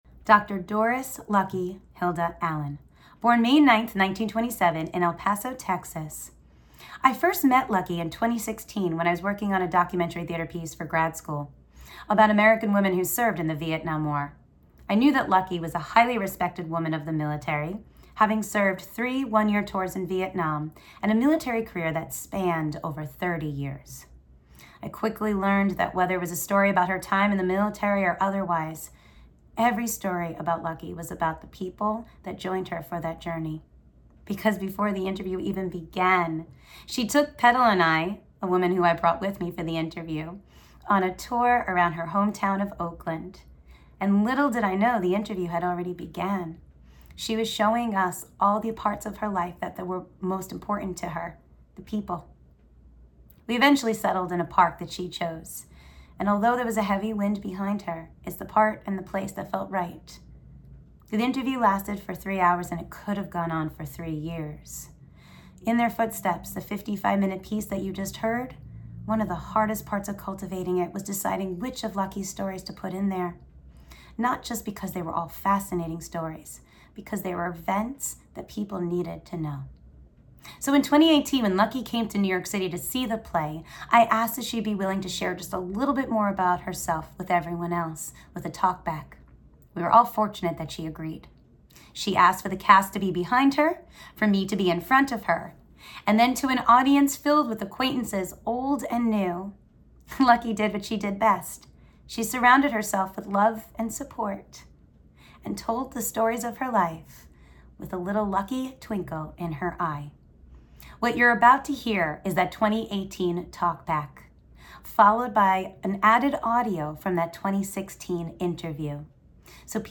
A compilation of interviews
Watch the interview compilation here.